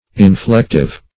Inflective \In*flect"ive\, a.